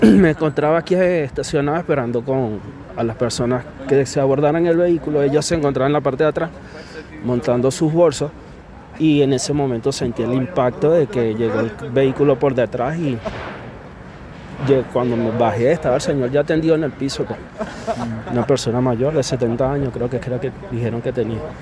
Radio Bío Bío conversó con el conductor de aplicación que fue chocado en este incidente.